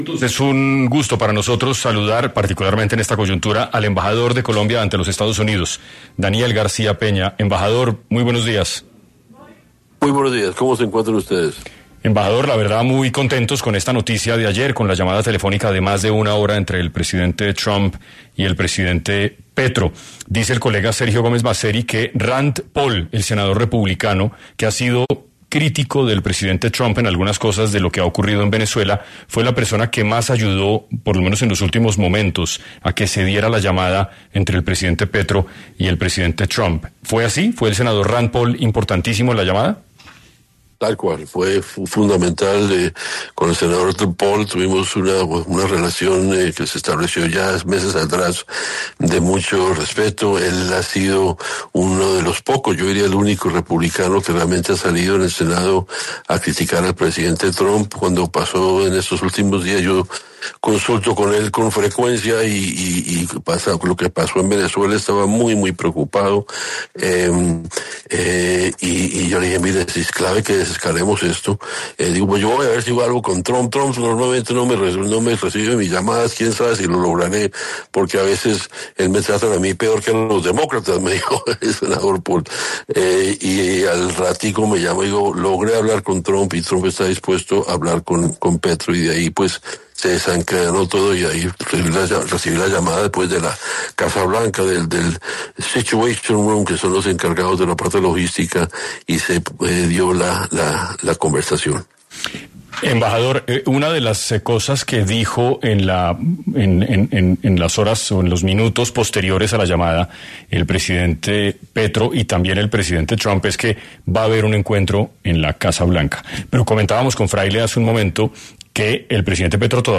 Daniel García-Peña, embajador de Colombia en Estados Unidos, habló de la reunión para desescalar el conflicto entre los mandatarios.
El funcionario dio detalles en 6AM de Caracol Radio sobre la conversación telefónica que se dio entre Donald Trump y Gustavo Petro, la cual sirvió justamente para desescalar las diferencias y terminó con una invitación formal a reunirse en la Casa Blanca.